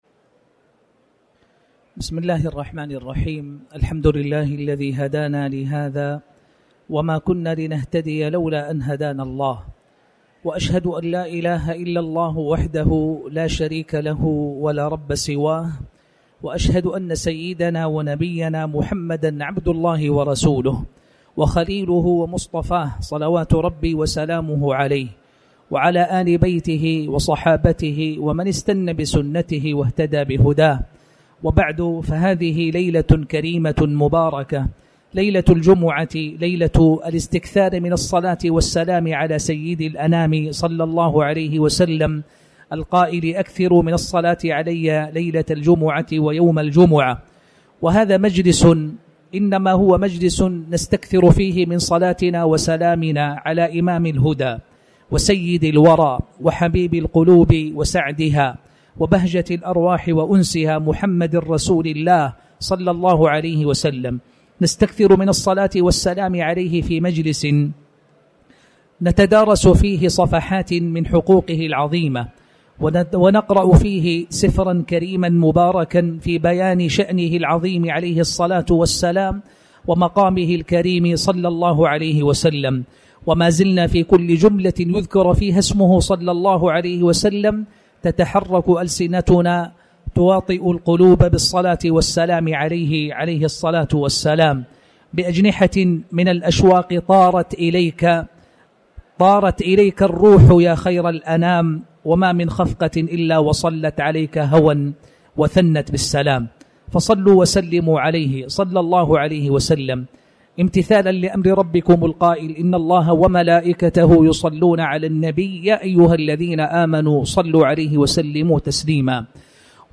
تاريخ النشر ٢١ ربيع الأول ١٤٤٠ هـ المكان: المسجد الحرام الشيخ